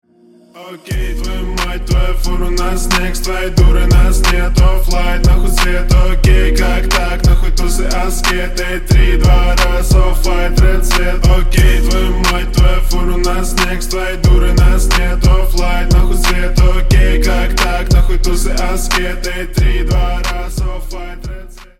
громкие
русский рэп